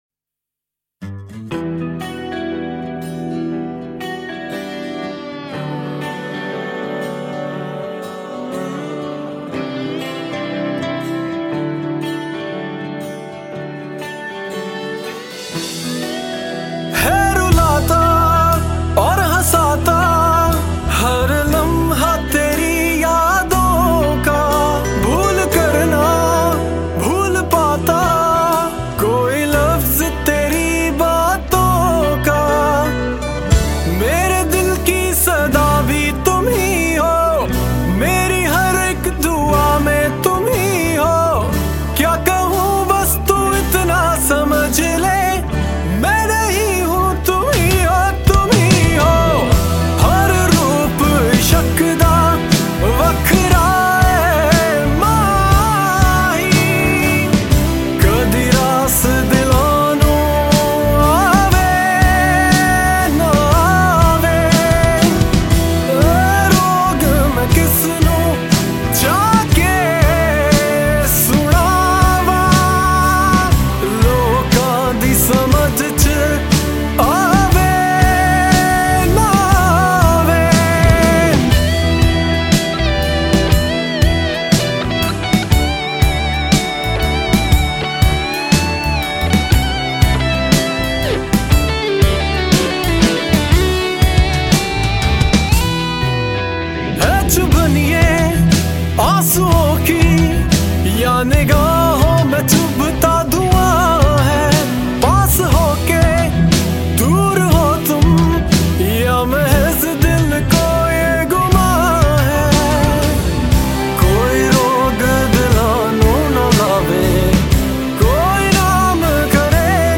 Latest Single Pop Songs